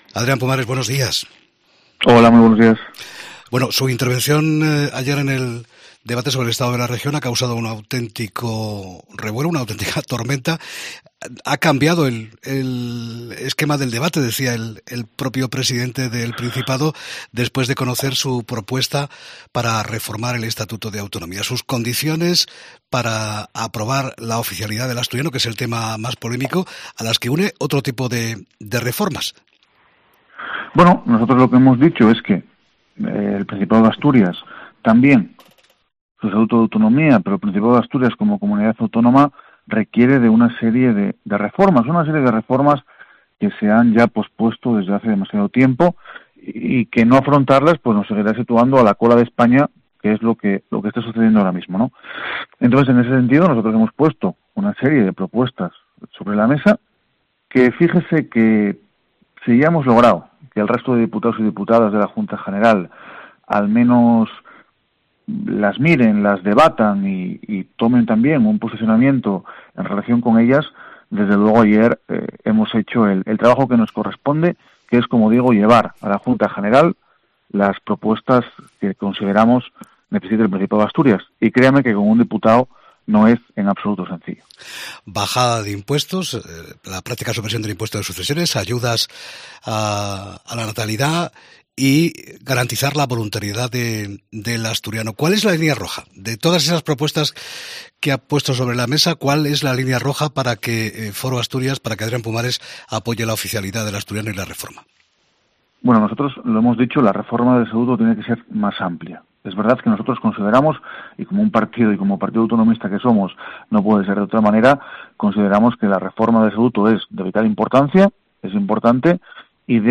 Entrevista con Adrián Pumares sobre la reforma del Estatuto de Autonomía